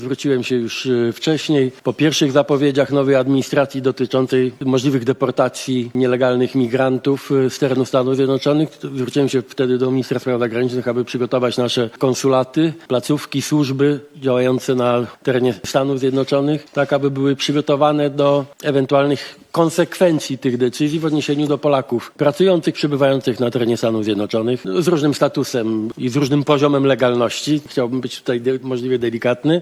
– „Każdy Polak i każda Polka będą serdecznie witani w kraju. Każdy znajdzie miejsce w swojej ojczyźnie. Tutaj każdy znajdzie swoją Amerykę” – powiedział premier Donald Tusk przed dzisiejszym posiedzeniem Rady Ministrów.